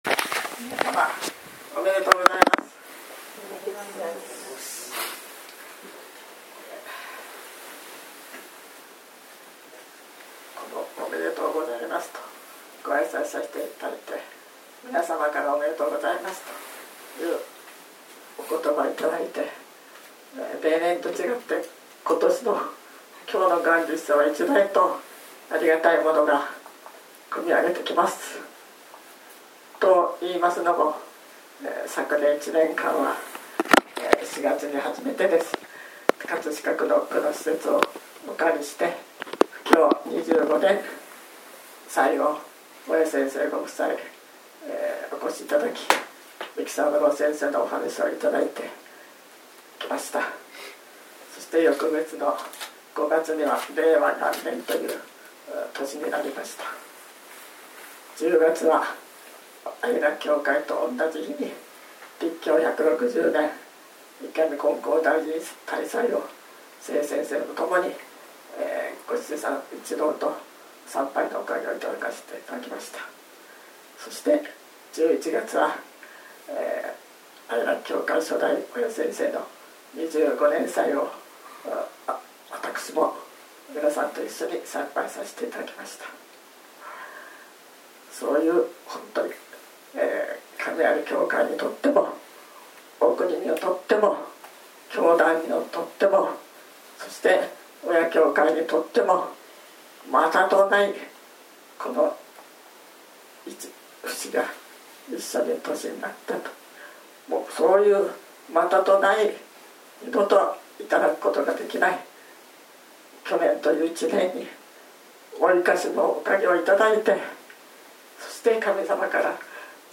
元日祭教話